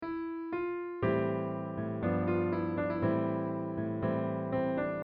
Blizzard-Am7
Blizzard-Am7.mp3